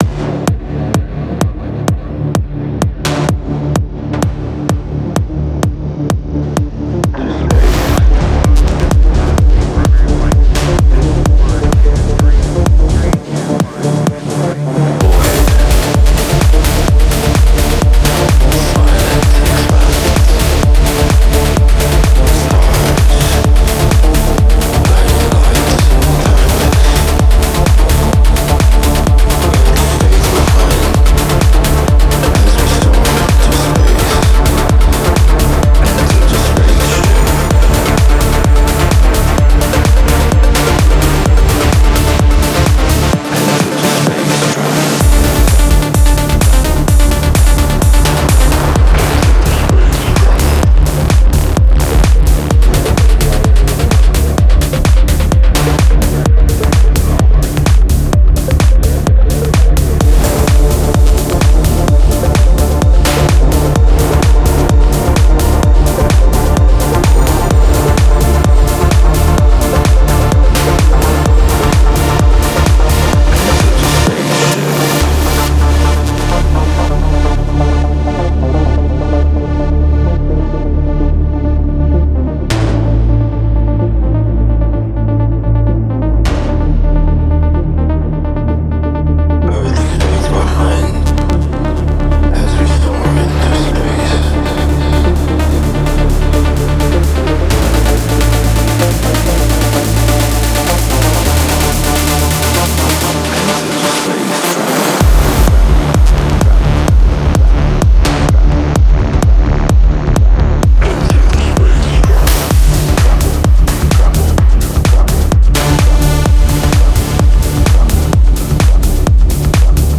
With its dark, nerve-wracking atmosphere